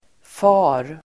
Uttal: [fa:r el .²f'a:der]